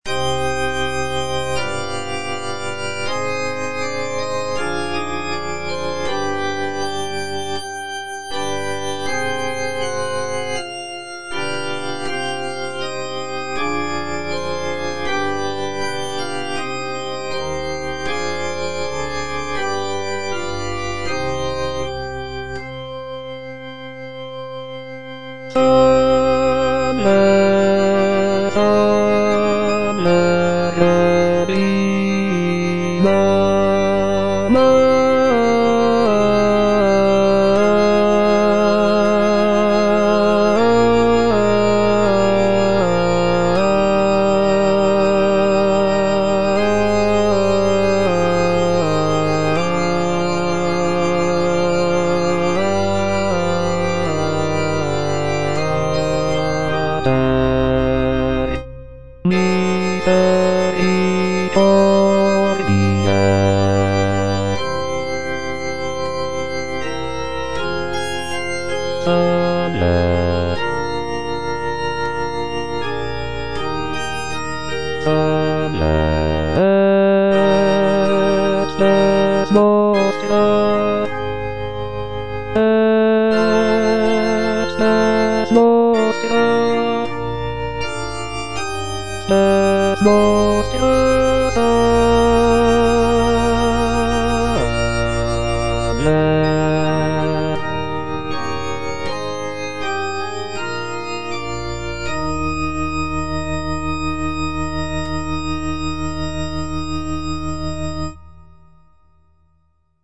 Choralplayer playing Salve Regina in C minor by G.B. Pergolesi based on the edition IMSLP #127588 (Breitkopf & Härtel, 15657)
G.B. PERGOLESI - SALVE REGINA IN C MINOR Salve Regina - Bass (Voice with metronome) Ads stop: auto-stop